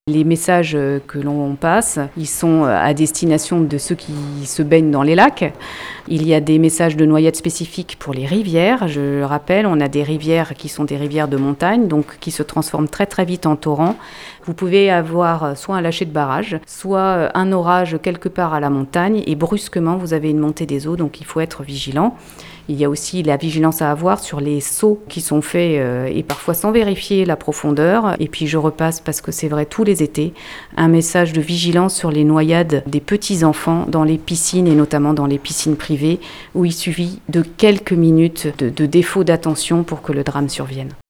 Emmanuelle Dubée est la préfète du département.